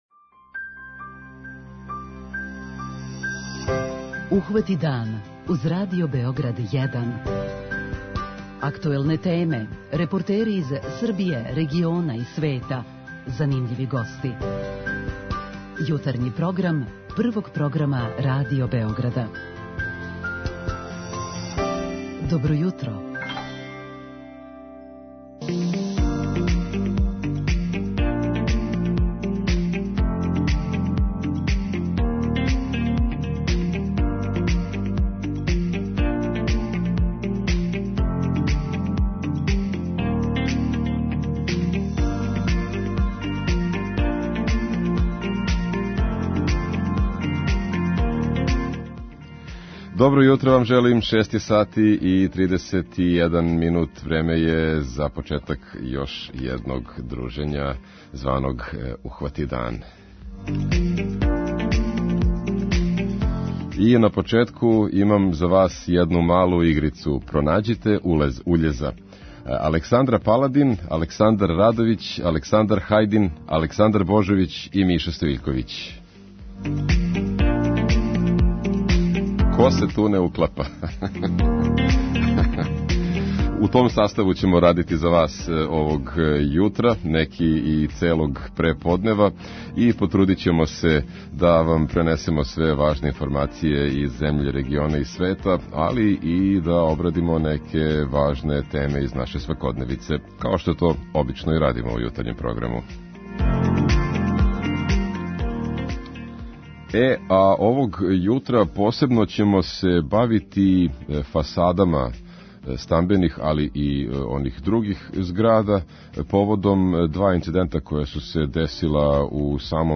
О овој теми чућемо и мишљења слушалаца у нашој редовној рубрици 'Питање јутра'.
О овој теми чућемо и мишљења слушалаца у нашој редовној рубрици "Питање јутра". преузми : 37.78 MB Ухвати дан Autor: Група аутора Јутарњи програм Радио Београда 1!